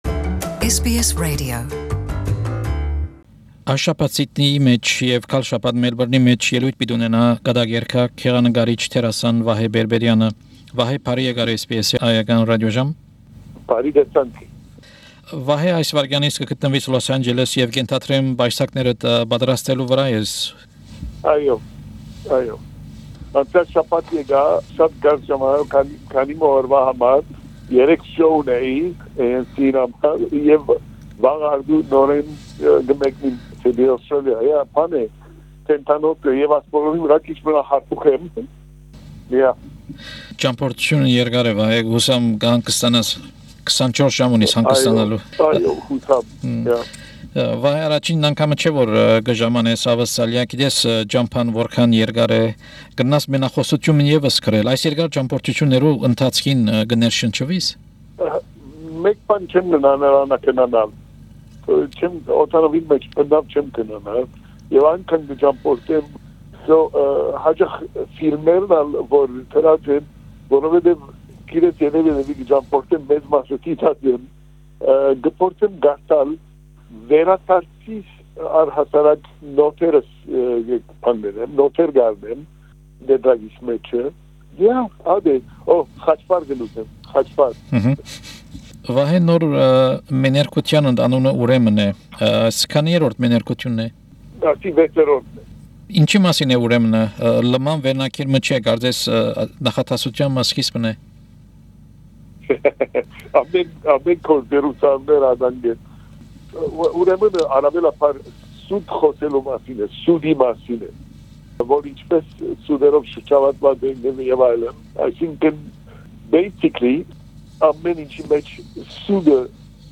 Հարցազրոյց մենախօսութեան վարպետ, դերասան վիպագիր, նկարիչ, բեմադրիչ Վահէ Պերպերեանի հետ, որ ելոյթներ պիտի ունենայ Սիտնիի և Մելպըրնի մէջ իր նոր մենախօսութեամբ որ կը կոչուի “Ուրեմն”: